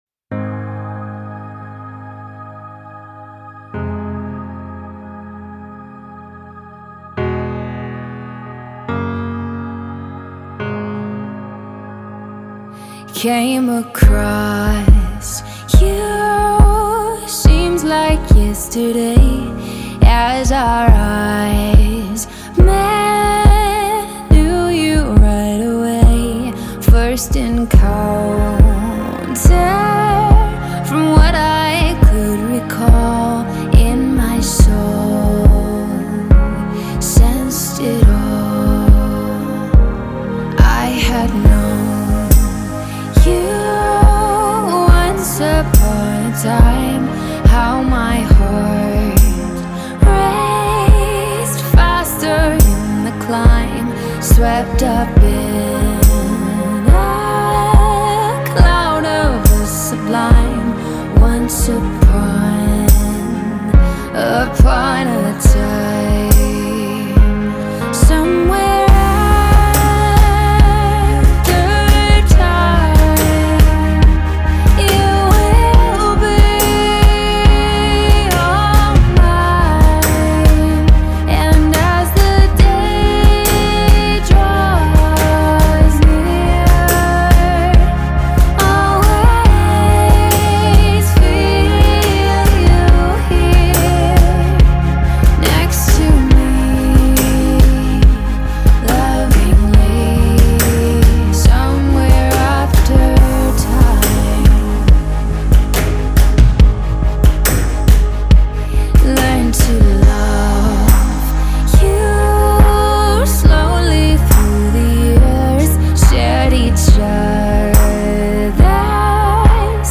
"Somewhere After Time" (pop/AC)